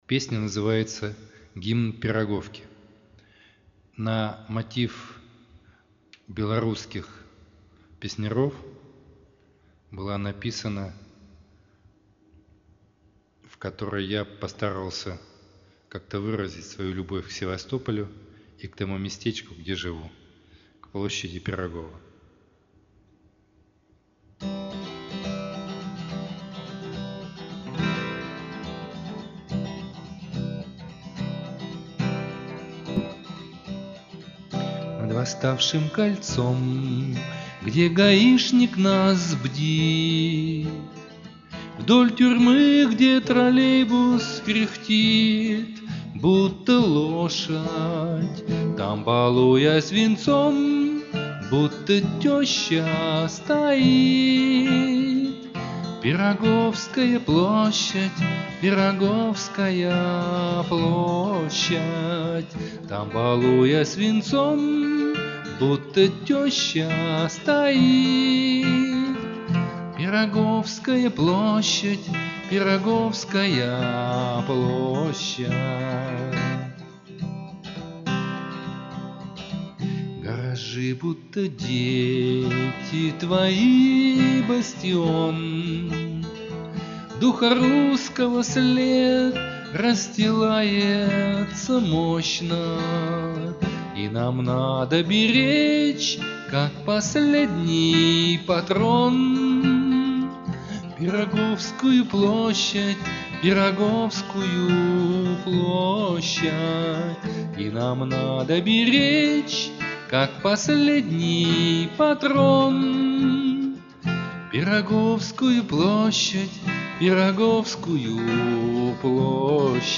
Авторская песня
скачать авторское исполнение